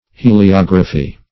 Heliography \He`li*og"ra*phy\, n.